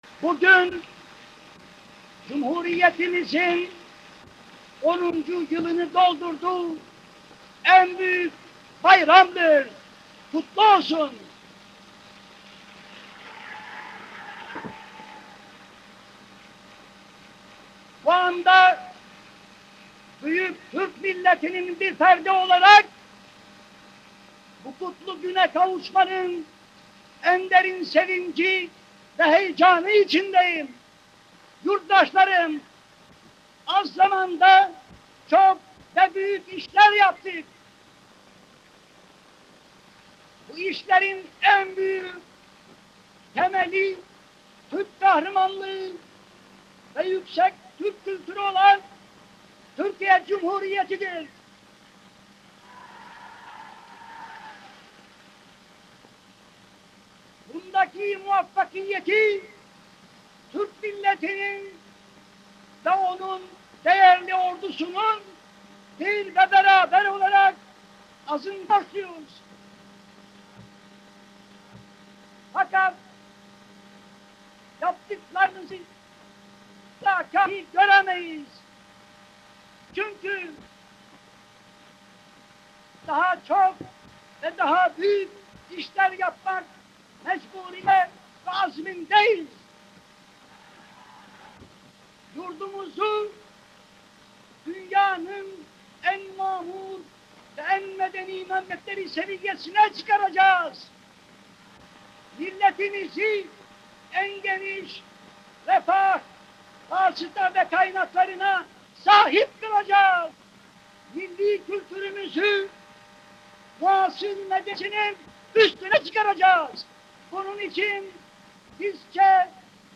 ataturk_voice_restorated